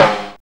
SMALL HI SN.wav